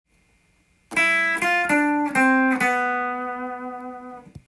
エレキギターで弾ける【Gミクソリディアンフレーズ集】オリジナルtab譜つくってみました
②は、ミの音から始まるので少し緊張感がある雰囲気がしますが
すぐにG７のコードトーンになるファの音に移動し
最後はG７のコードトーン　３度のシの音に着地します。